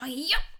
SFX_Battle_Vesna_Attack_11.wav